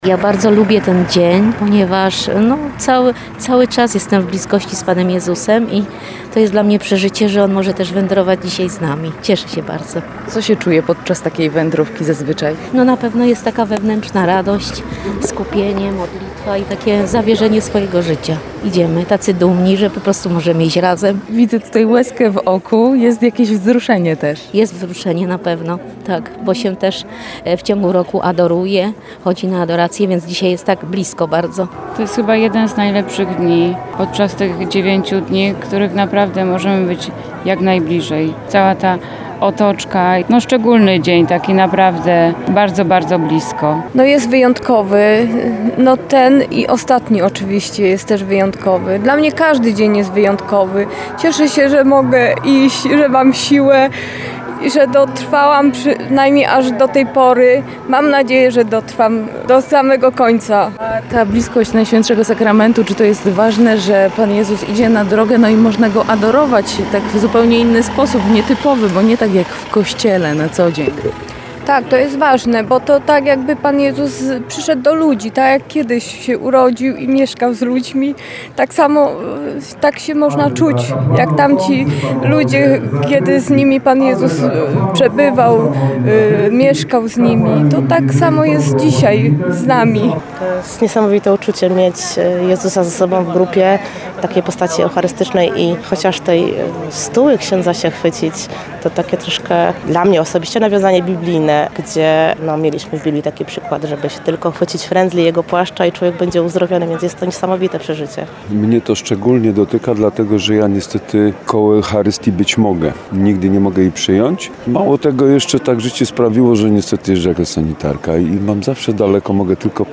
– mówią pielgrzymi z grupy 7.